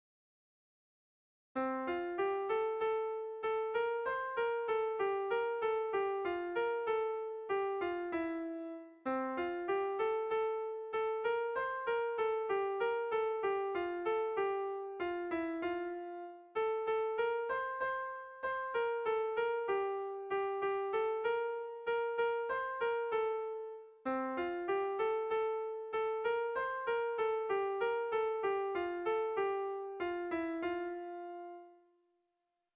Zortziko handia (hg) / Lau puntuko handia (ip)
A1A2BA2